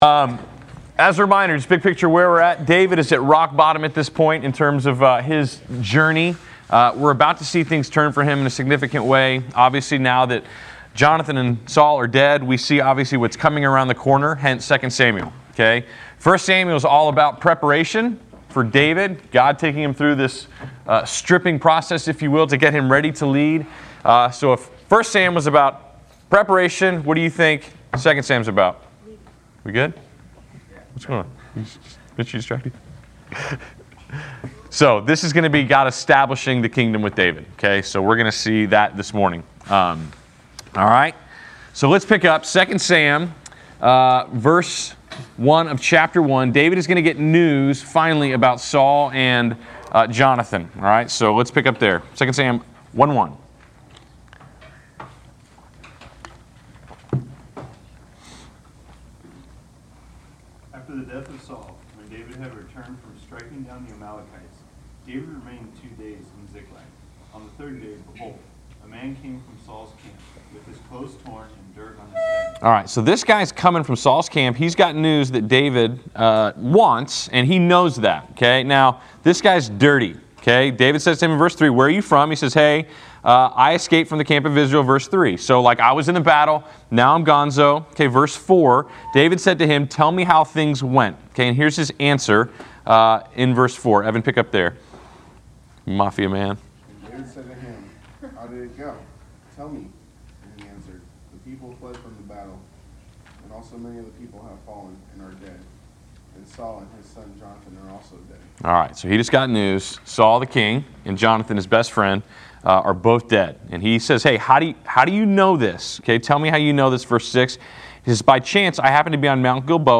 Class Session Audio April 04